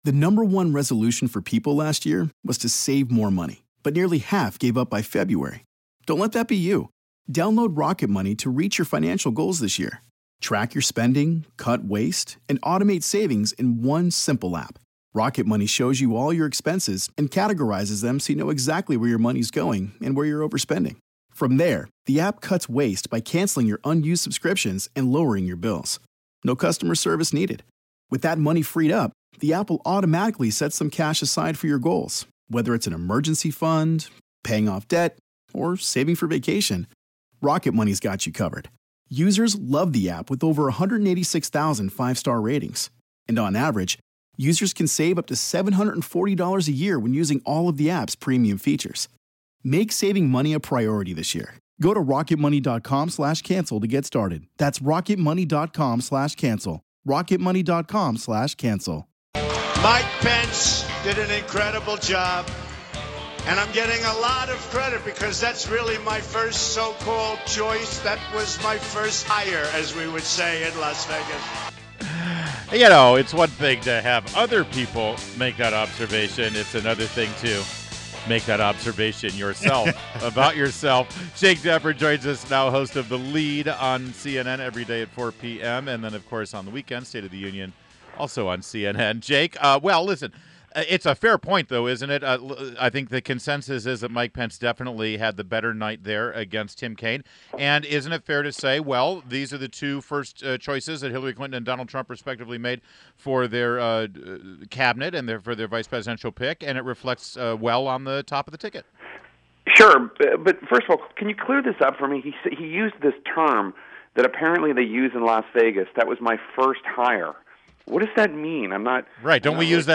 WMAL Interview - JAKE TAPPER - 10-06-16
INTERVIEW -- JAKE TAPPER - Host of THE LEAD and STATE OF THE UNION on CNN